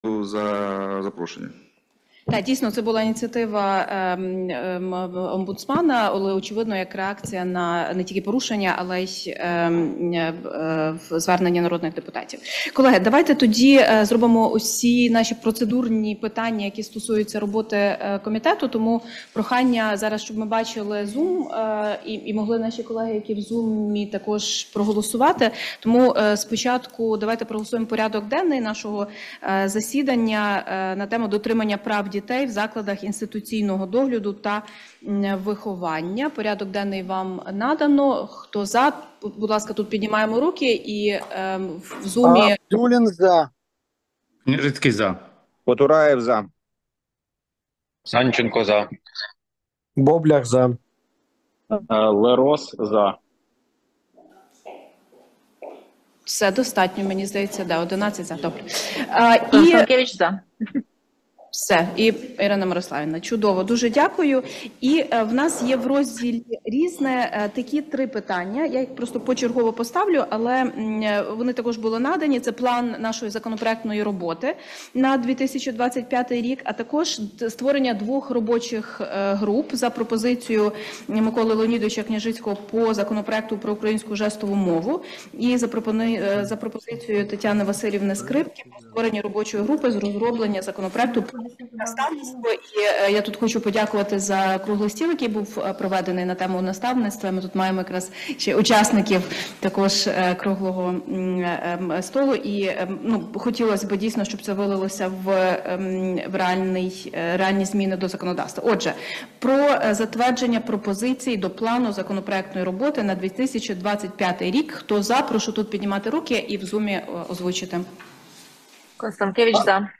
Назва файлу - Виїзне засідання у Центрі захисту прав дитини 4 грудня 2024 року (голосування)